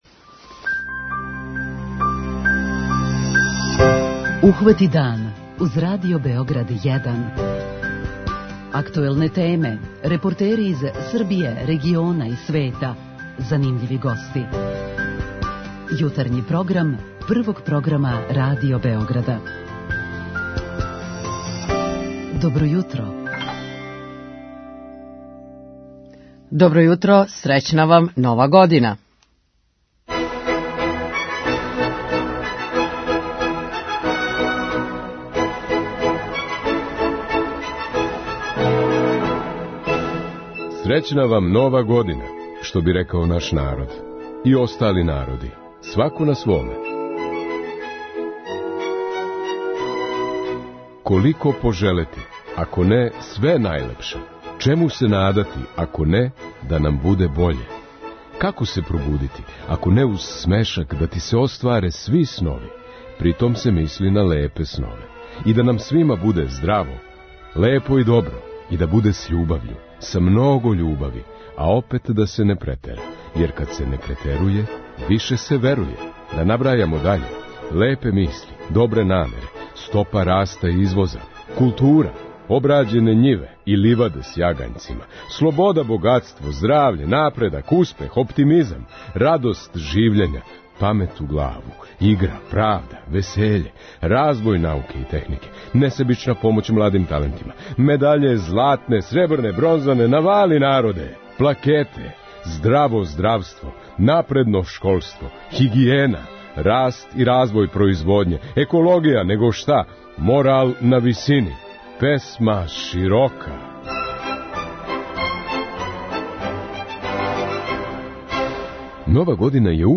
Наши дописници ће нам јавити како се у њиховим градовима прослављало током најлуђе ноћи. Као и сваке године, позивамо вас да дођете у наш комшилук, јер тачно у подне у центру Београда почиње традиционална 'Улица отвореног срца'.